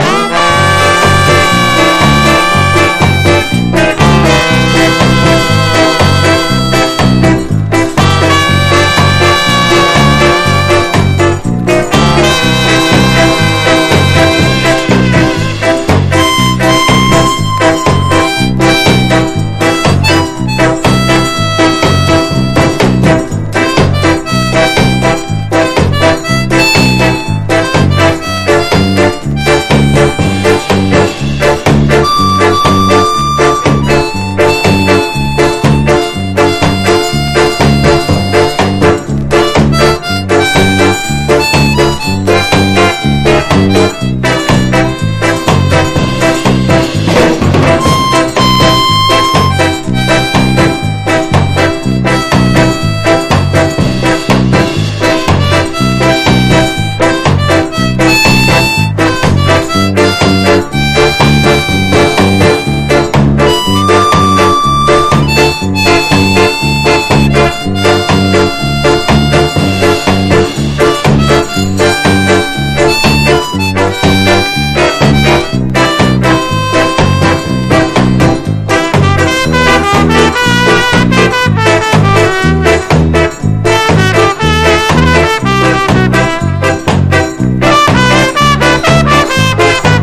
SKA / ROCK STEADY